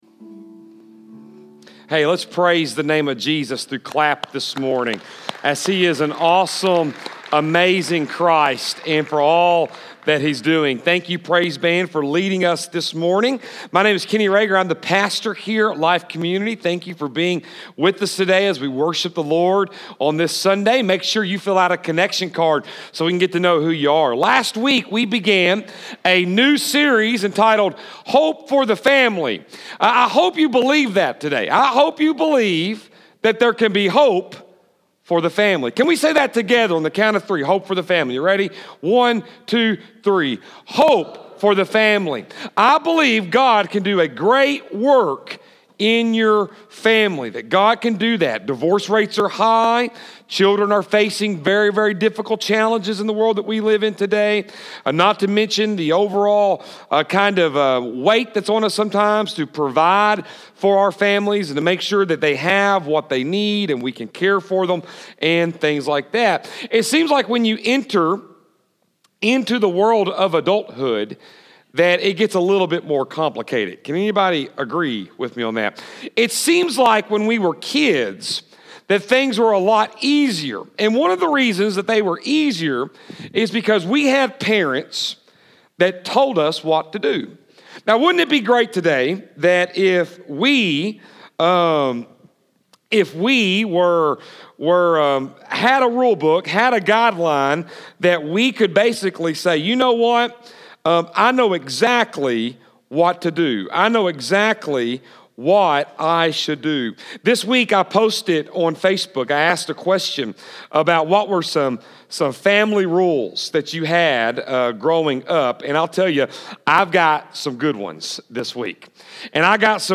February 27, 2017 Hope for the Family- The Blessed Family Service Type: Sunday AM Second message in the series "Hope for the Family" focusing on Abraham's obedience and how it lead to his entire family being blessed.